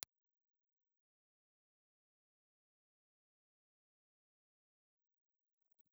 Impulse Response File:
Impulse Response file of the Shure 300 ribbon microphone.
Shure300_IR.wav